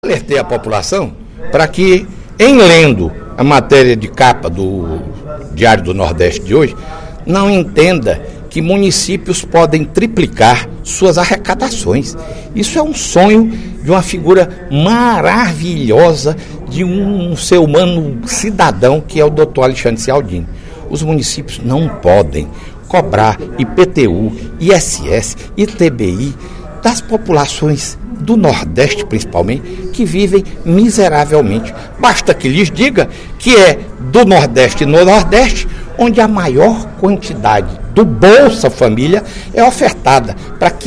O deputado Fernando Hugo (SD), em pronunciamento  no primeiro expediente da sessão plenária desta sexta-feira (05/06), discordou da avaliação do ex-secretário de Finanças de Fortaleza e auditor fiscal da Secretaria da Fazenda, Alexandre Cialdini, sobre  a arrecadação do município.